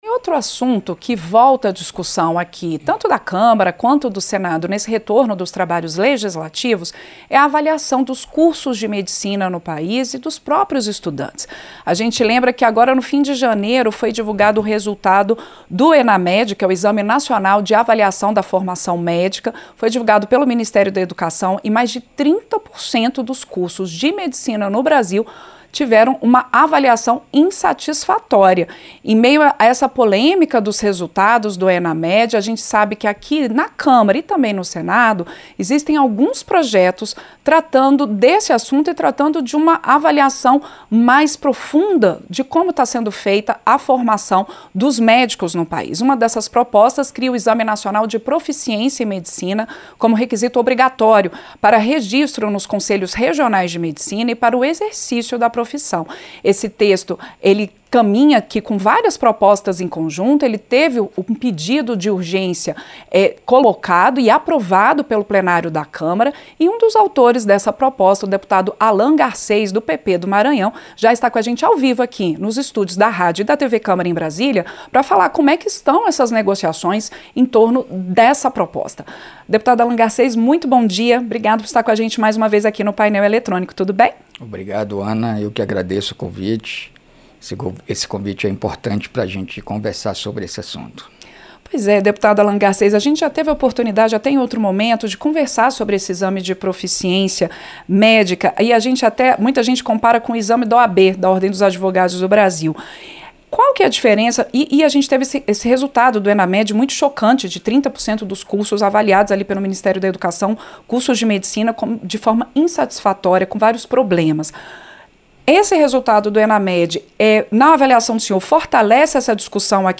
Entrevista - Dep. Allan Garcês (PP-MA)